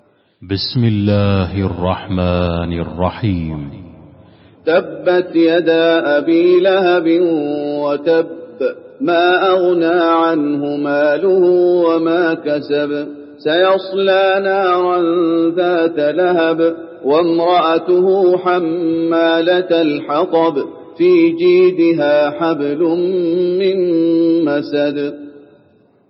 المكان: المسجد النبوي المسد